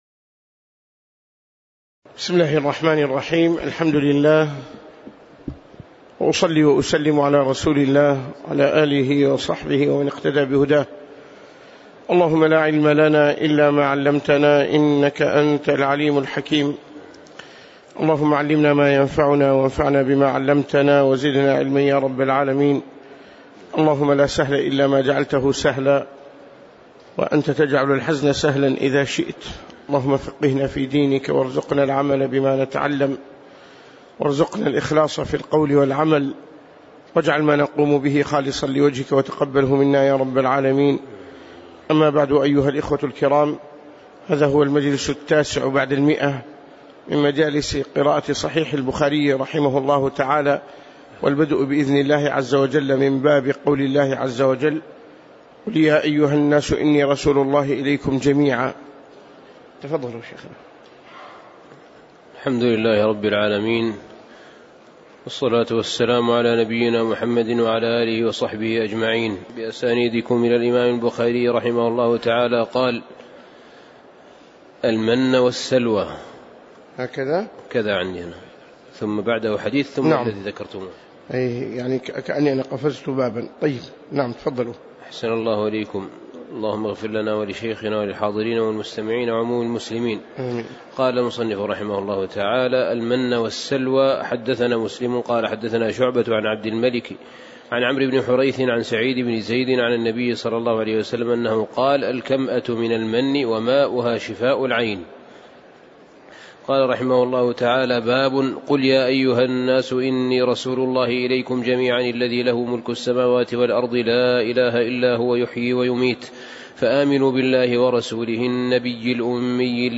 تاريخ النشر ١ شعبان ١٤٣٨ هـ المكان: المسجد النبوي الشيخ